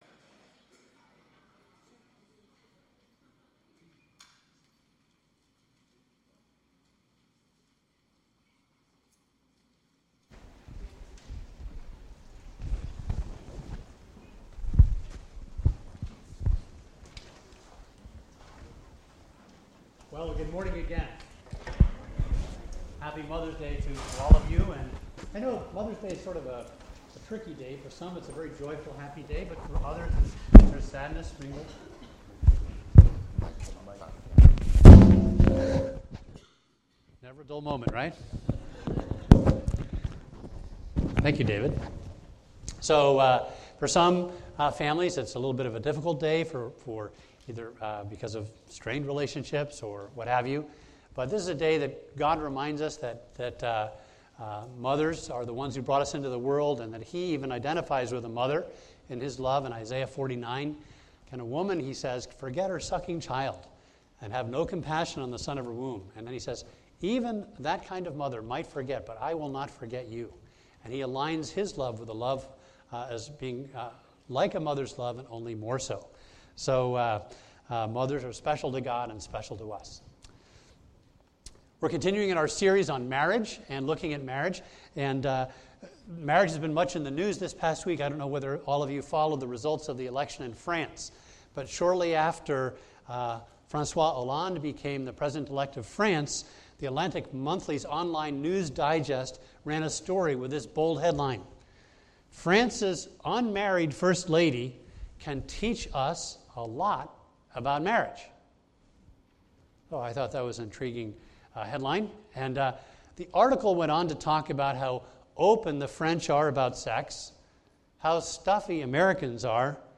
A message from the series "The Meaning of Marriage."